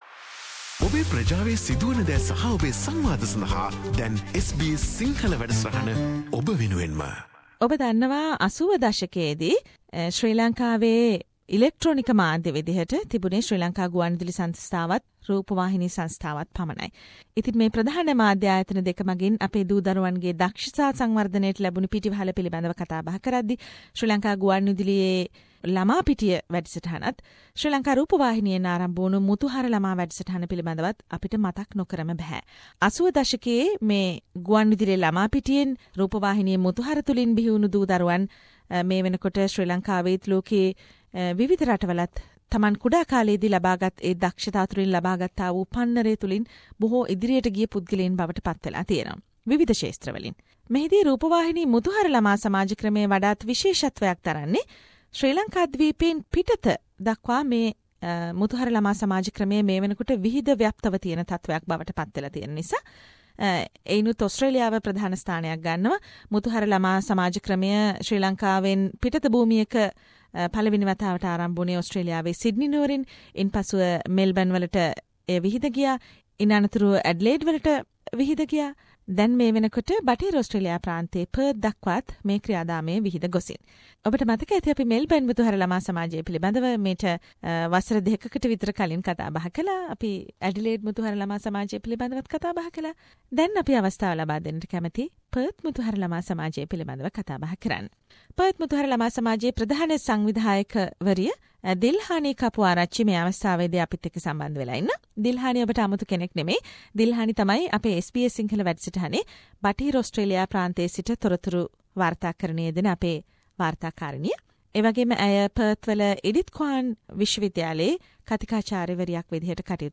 Perth Muthuhara is to provide guidance for children to improve their knowledge, attitudes, and skills as well as to enhance their arts, literature, social and cultural development. SBS Sinhala program invited Perth Muthuhara to share their experiences with our Sri Lankan community in Australia. Following representatives joined SBS Sinhala program to talk more on this